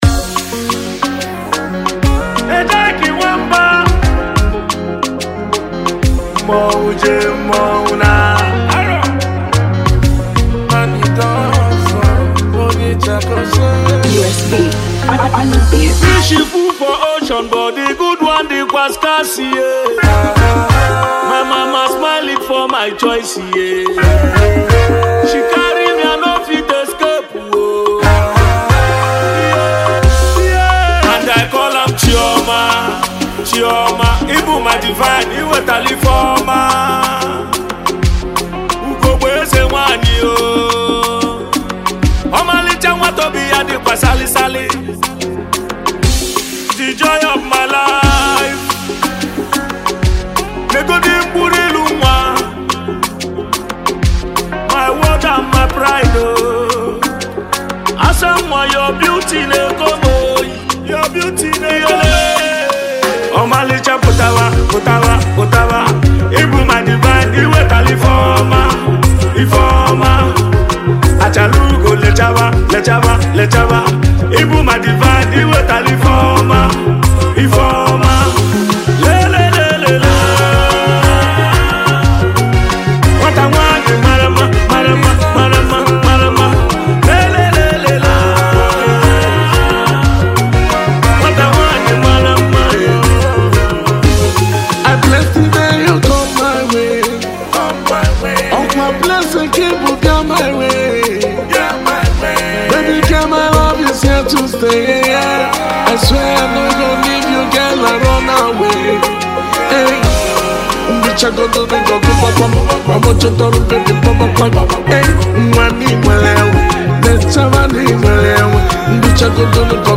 Highlife and Ogene Musician